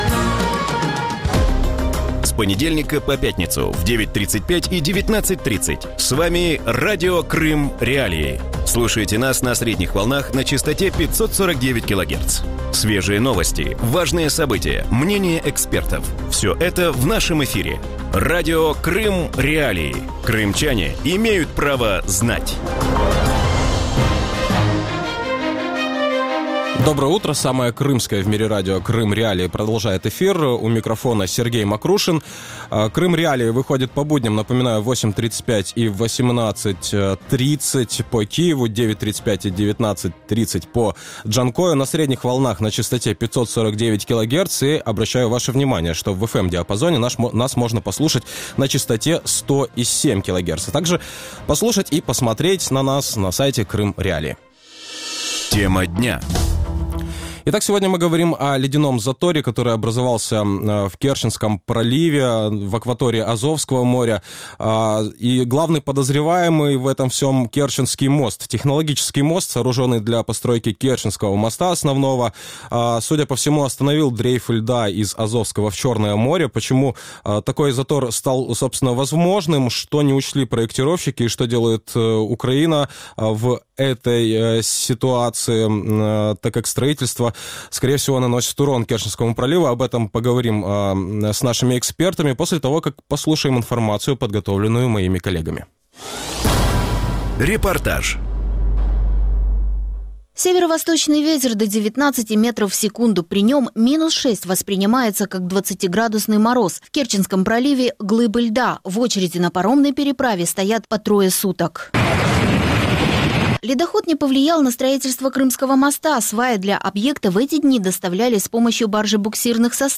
Вранці в ефірі Радіо Крим.Реалії говорять про крижаний затор який утворився в Керченській протоці на місці будівництва моста з Криму до сусідньої Росії. Технологічний міст, споруджений для побудови Керченського мосту, зупинив дрейф льоду з Азовського в Чорне море.